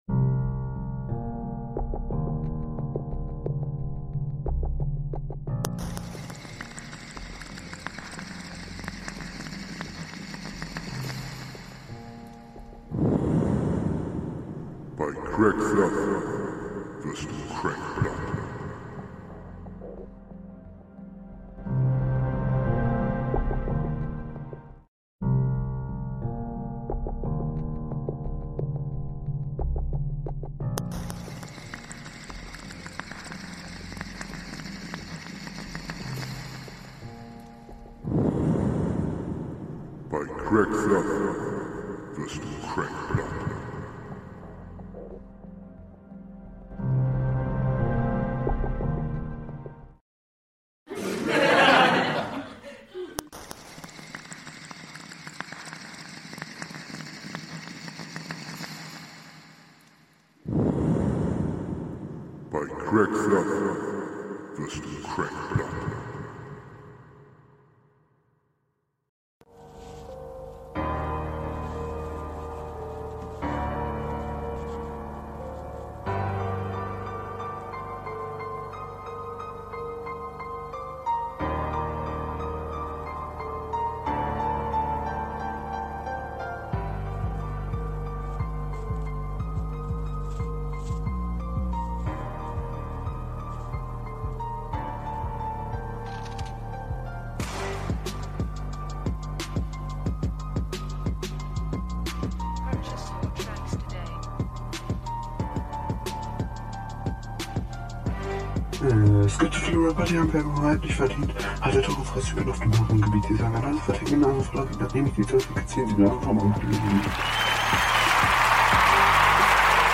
Mit sag ich mal mit elektrischer Musik...